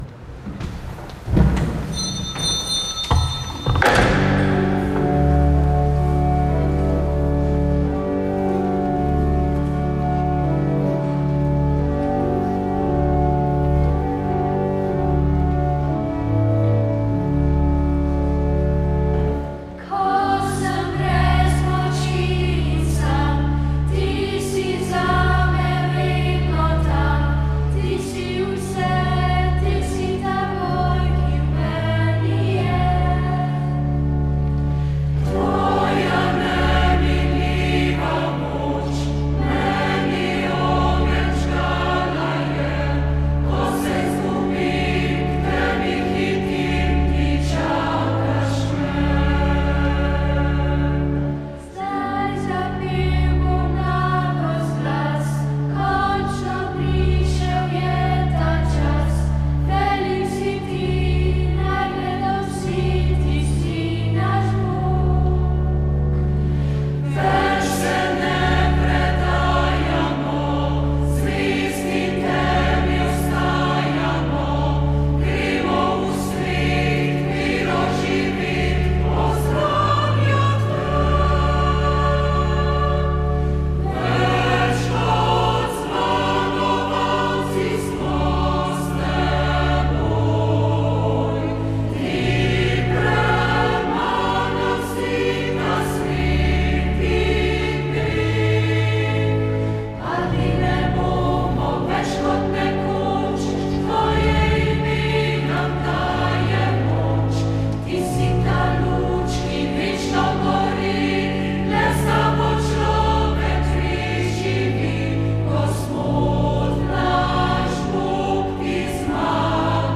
Sveta maša iz kapele Radia Ognjišče
Na god svetih Ane in Joahima, staršev device Marije, je v naši kapeli maševal nadškof Alojz Uran.